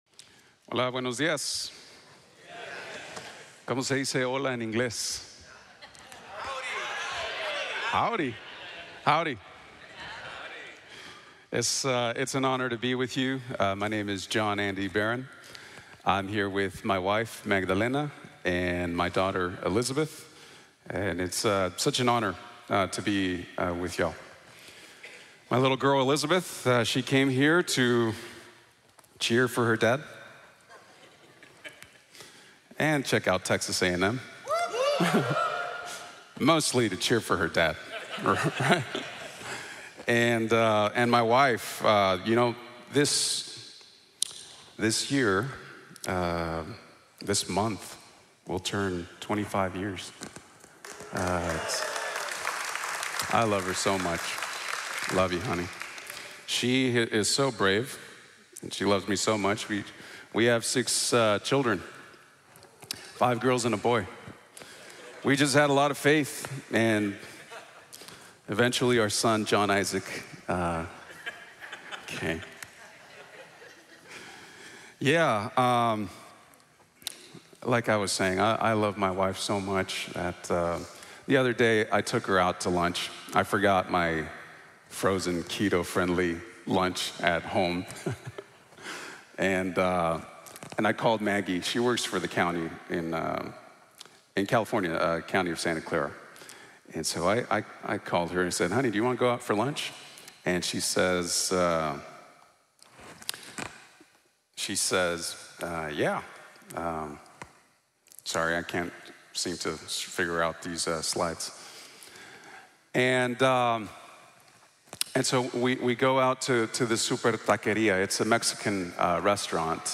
If you don't love people, it's just religion | Sermon | Grace Bible Church